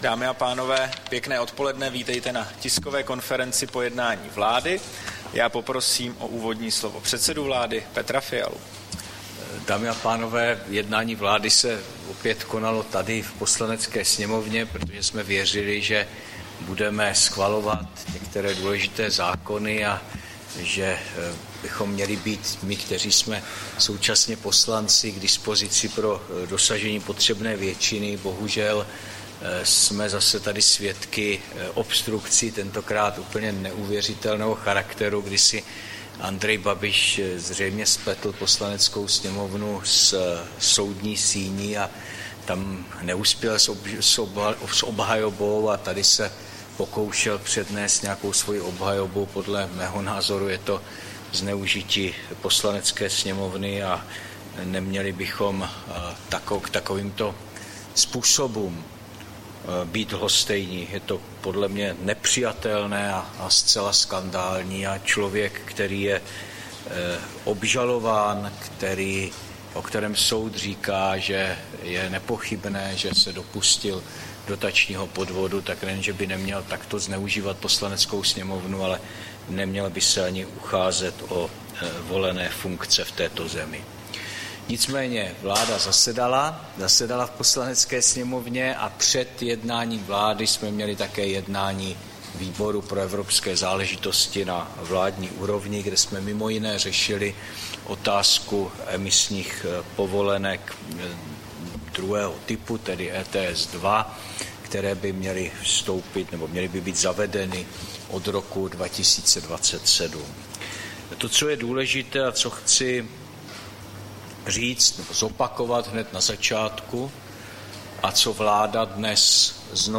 Tisková konference po jednání vlády, 25. června 2025